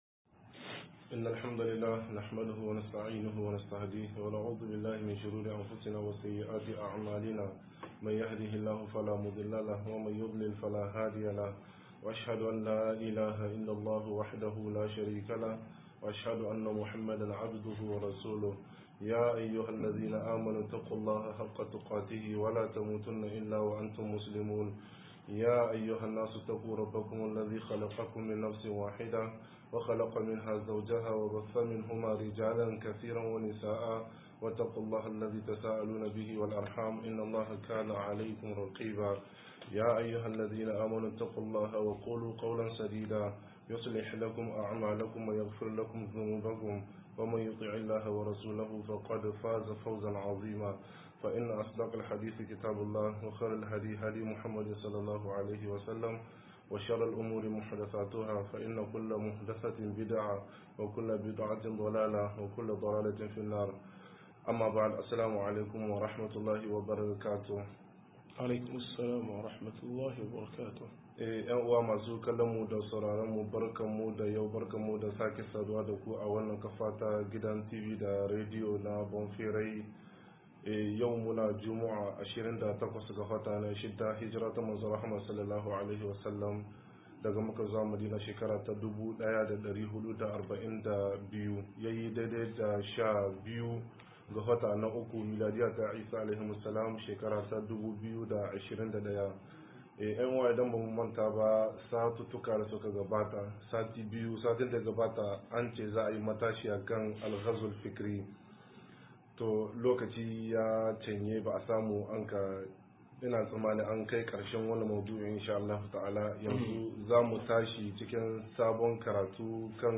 104-Miyagun laifufuka - MUHADARA